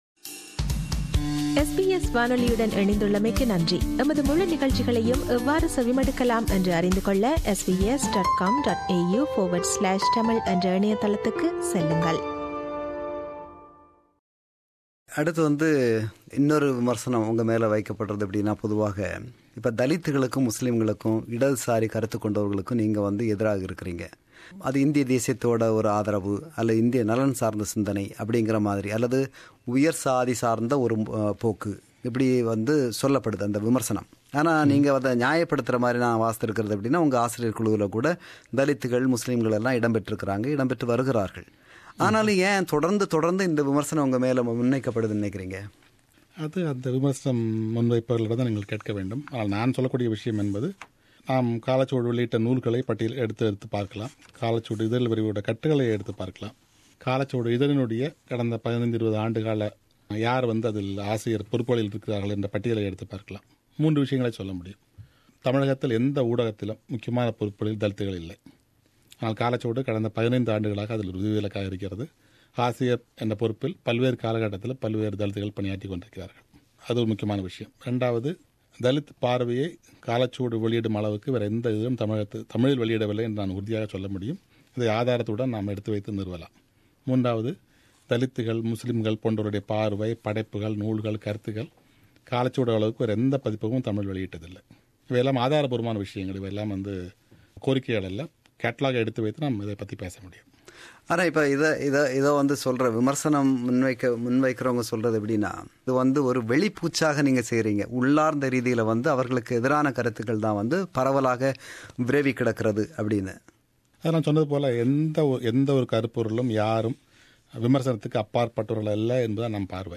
SBS ஒலிப்பதிவு கூடத்தில்
நேர்முகத்தின் நிறைவுப்பகுதி.